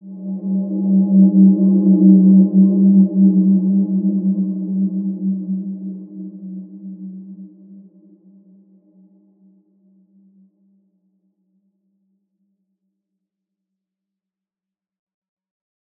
Wide-Dimension-G2-mf.wav